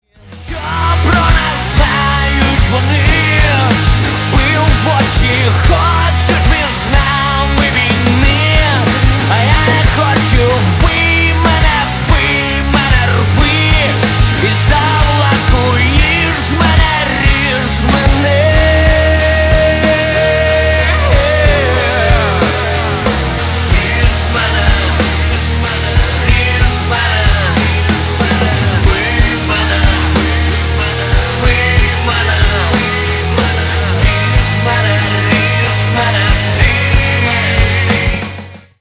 • Качество: 320, Stereo
мужской вокал
Драйвовые
Alternative Rock
украинский рок
grunge